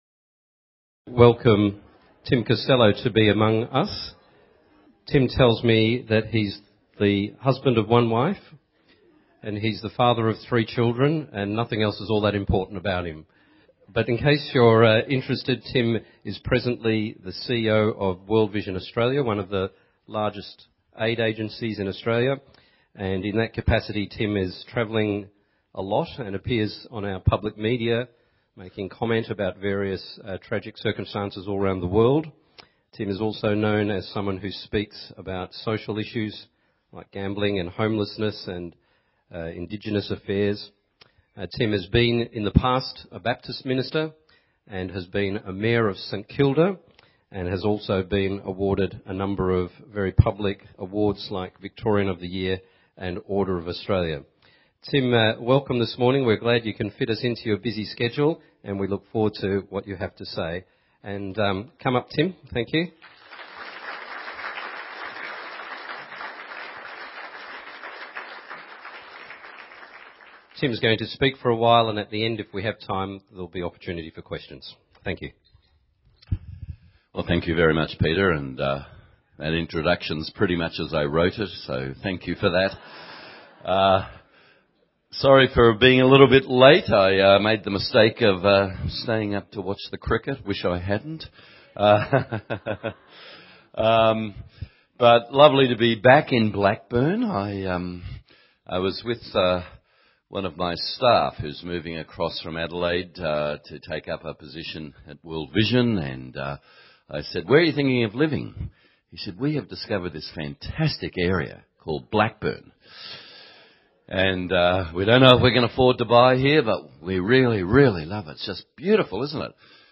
In this sermon, Tim Costello speaks on the theme of 'Breakfast with Tim Costello' as part of the series 'Standalone Talk'.